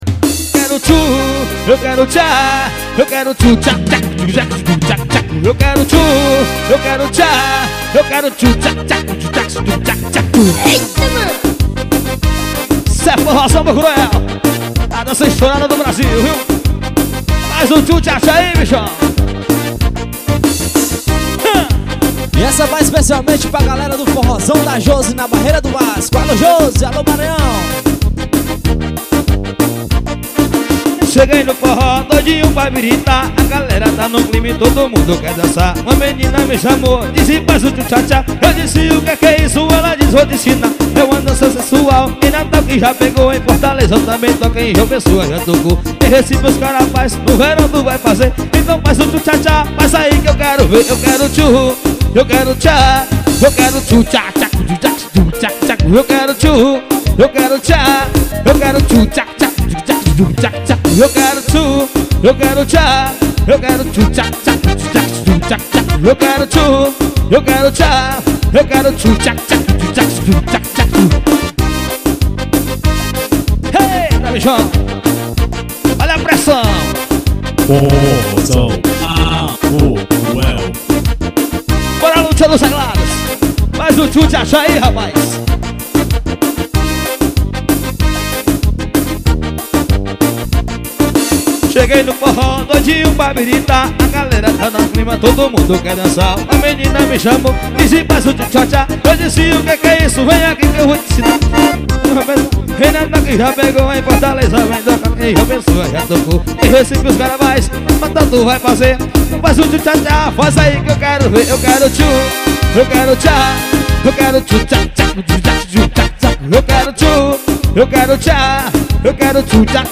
forró pégado.